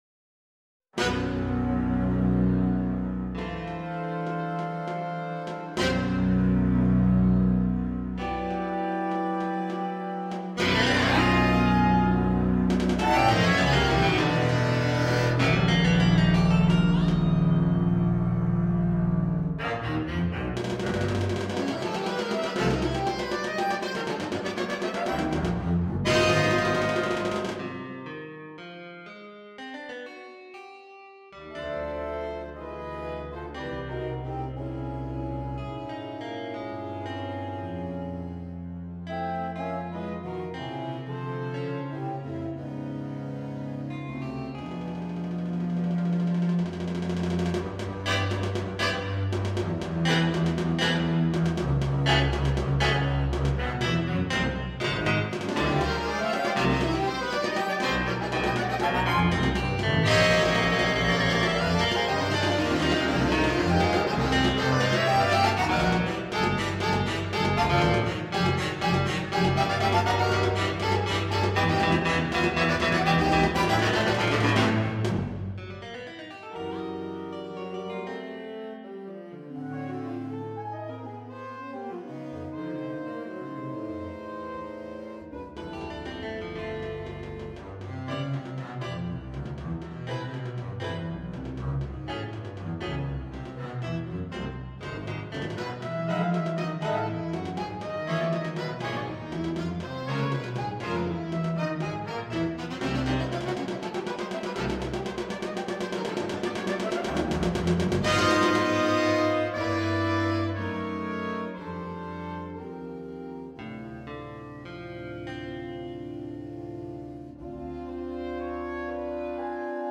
for sax quartet, double bass, electric guitar, cájon